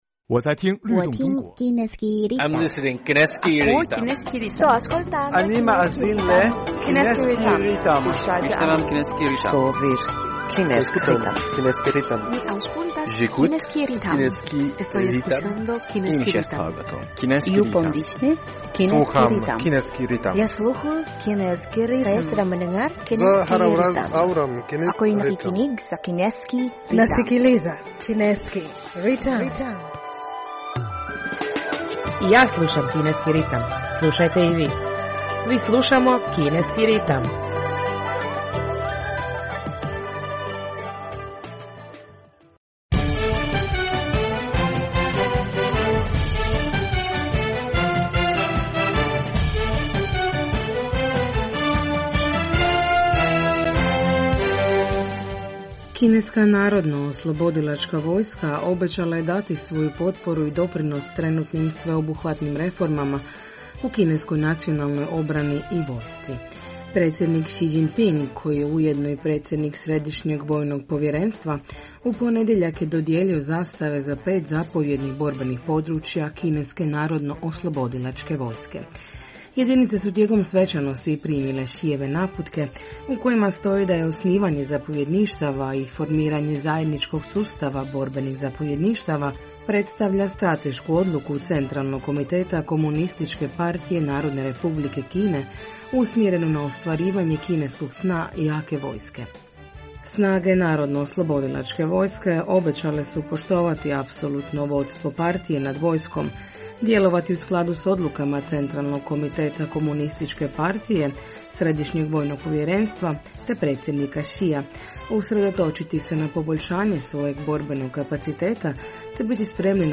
U današnjoj emisiji prije svega poslušajte novosti iz Kine i svijeta, a zatim našu rubriku "U fokusu Kine".